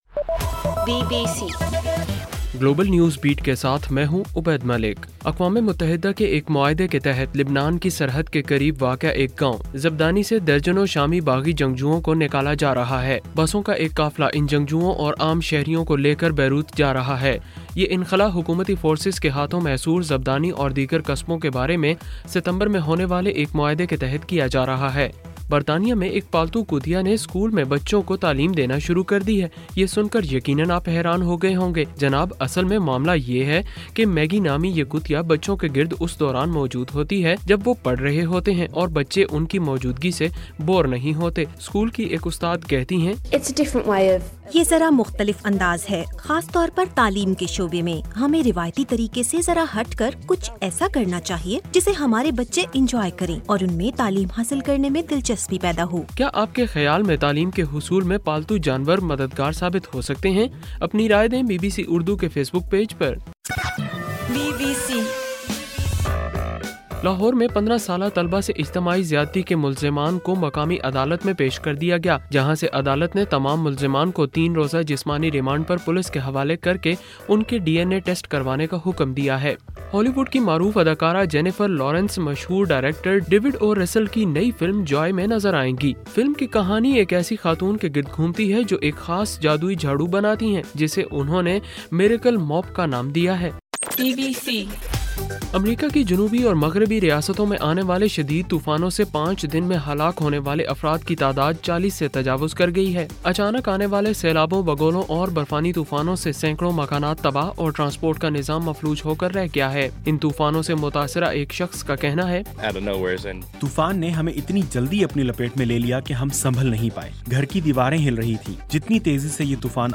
دسمبر 28: رات 10 بجے کا گلوبل نیوز بیٹ بُلیٹن